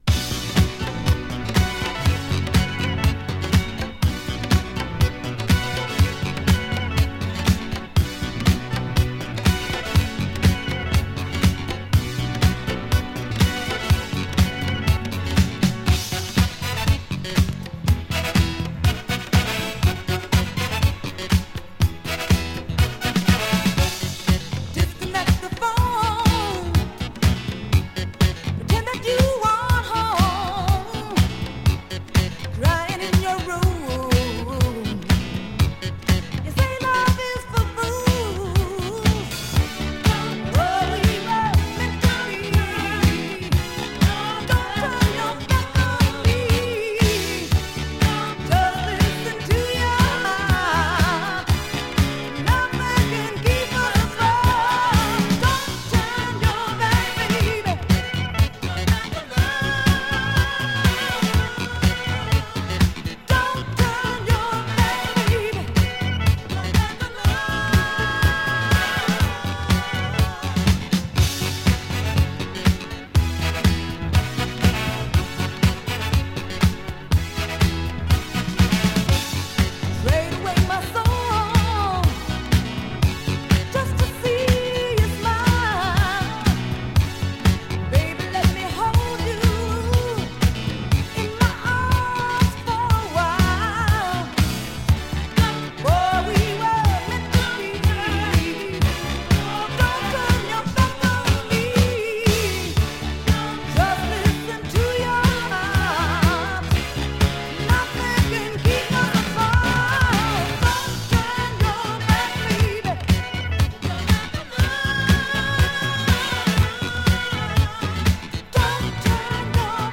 Garage Classic!
【DISCO】【BOOGIE】